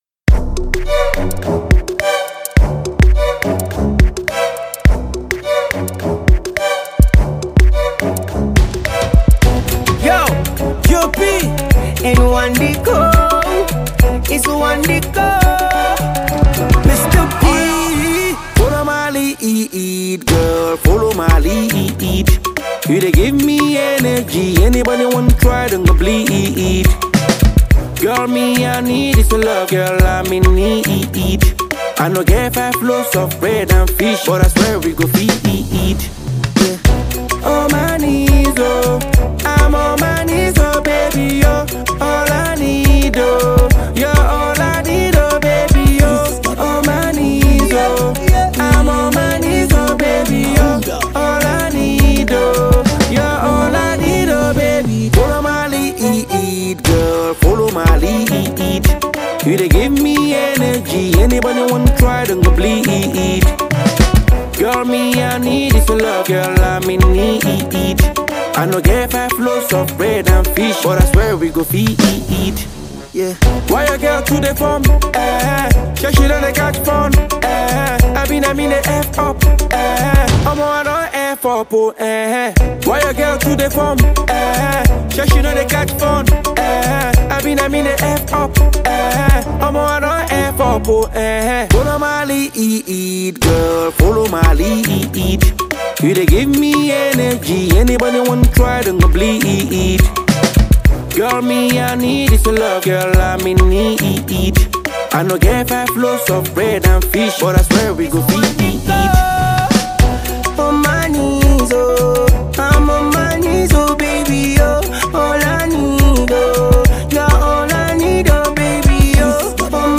Afropop R’n’B single
melodious singer